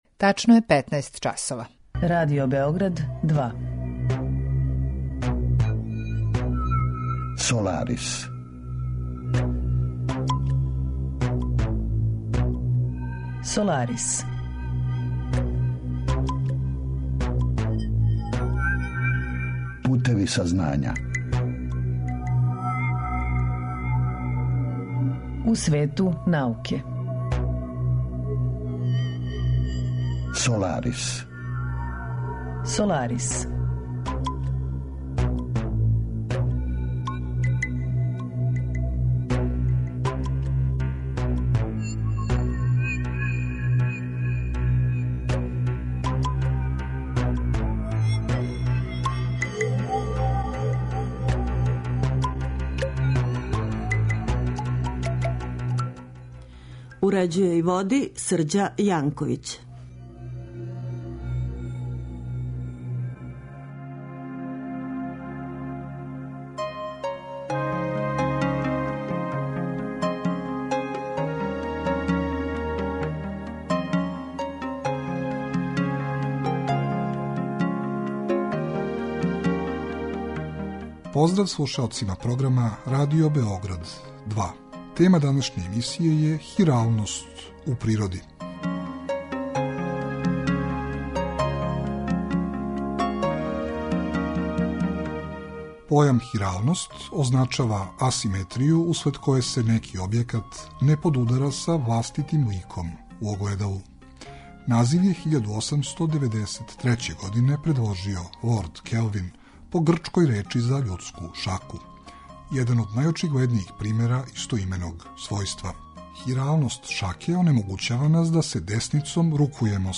Саговорник: проф. др